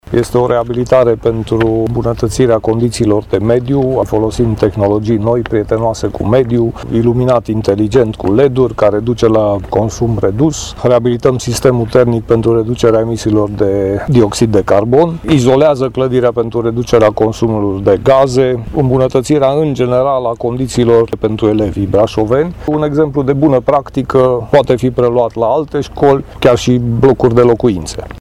Despre noile tehnologii aplicate, care duc la diminuarea poluării și a consumului de utilități, ne-a vorbit viceprimarul Brașovului, Laszlo Barabaș: